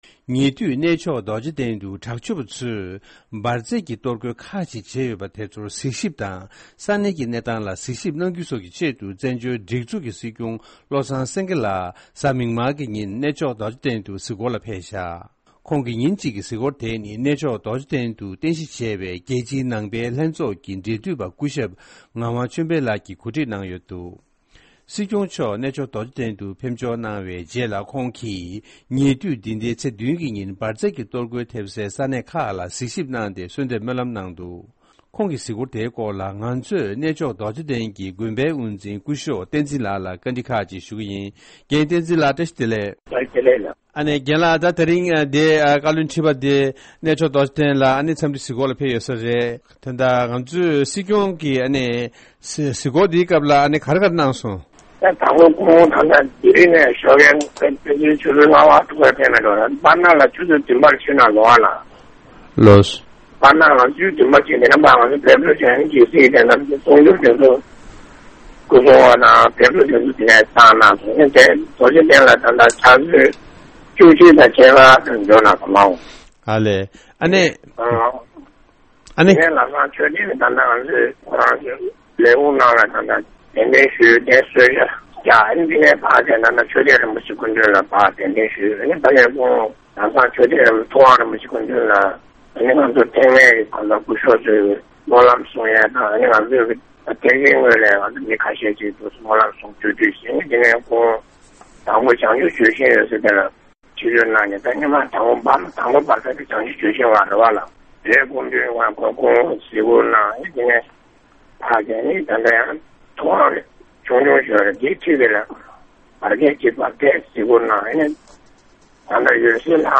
ཕྱོགས་བསྒྲིགས་ཀྱིས་སྙན་སྒྲོན་གནང་གི་རེད།